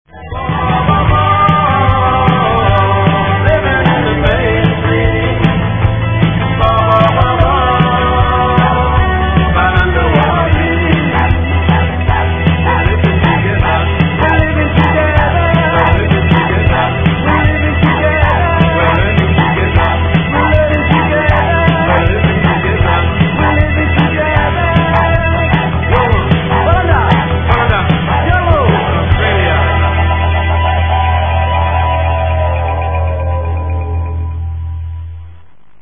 Non-traditional Didjeridu